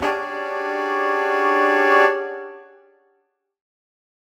Index of /musicradar/undercover-samples/Horn Swells/D
UC_HornSwell_Dmaj7b5.wav